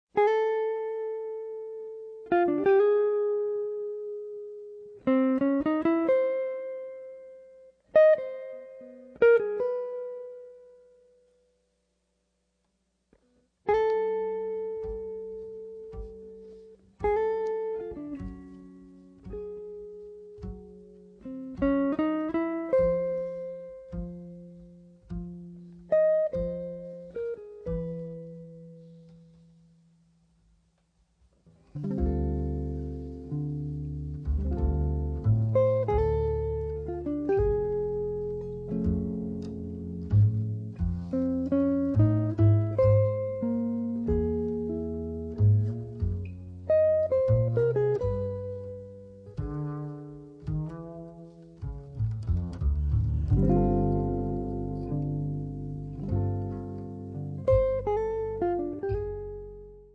chitarra
contrabbasso